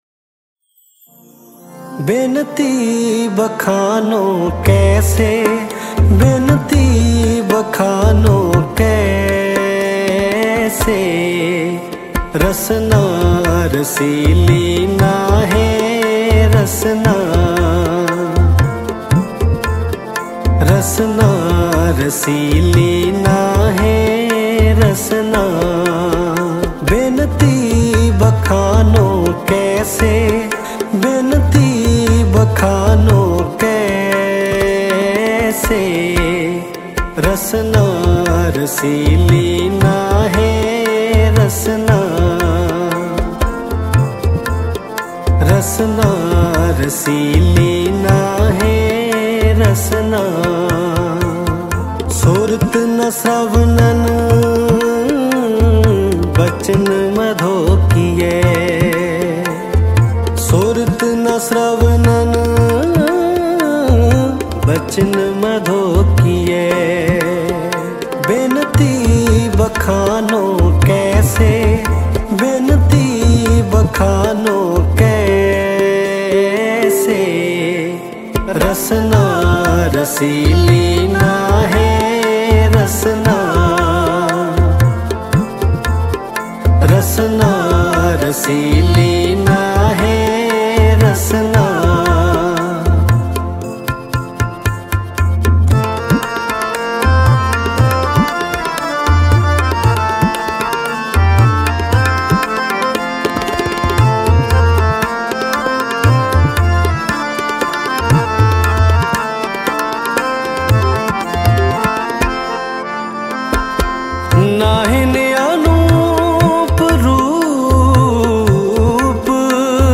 Mp3 Files / Gurbani Kirtan / 2025-Shabad Kirtan / Albums /